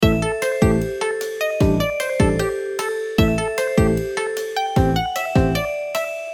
10秒BGM （103件）